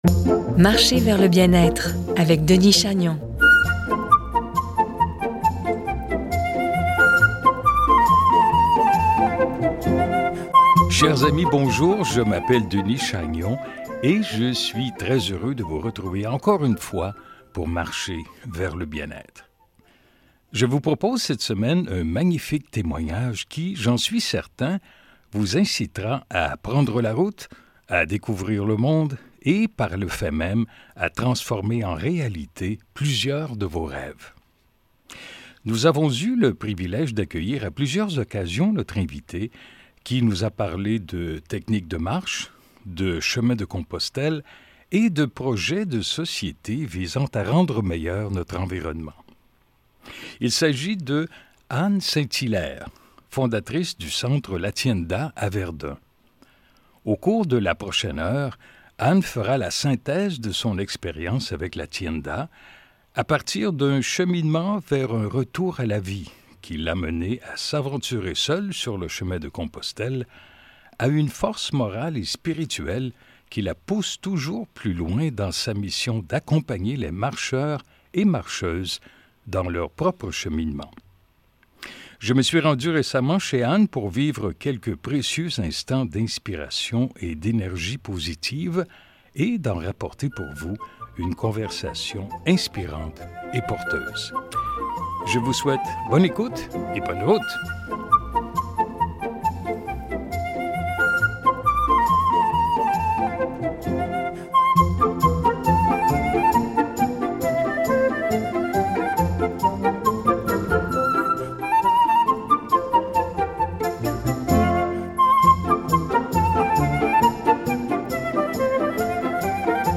17 décembre 2024 — Je vous propose cette semaine un magnifique témoignage qui j’en suis certain vous incitera à prendre la route, à découvrir le monde et par le fait même à réaliser vos rêves, petits et grands.